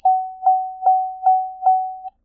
warning chime
car chime ding noise warning sound effect free sound royalty free Sound Effects